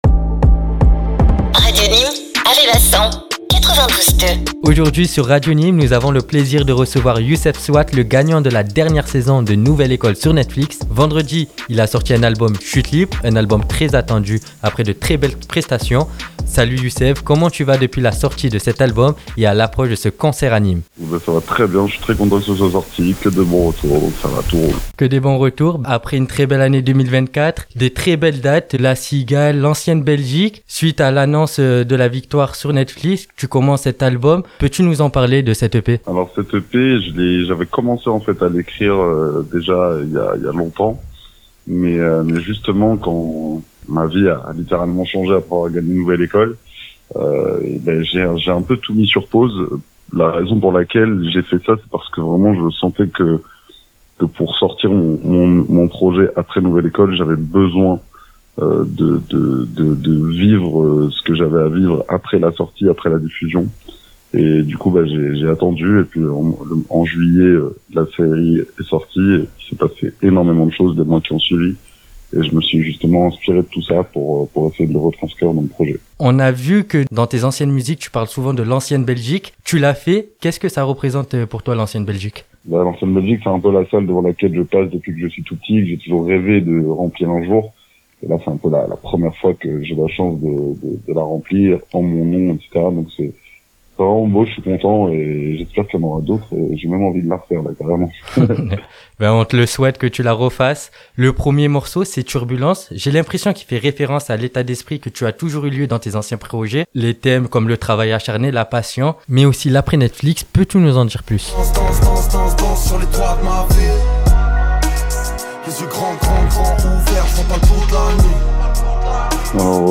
YOUSSEF SWATT'S - INTERVIEW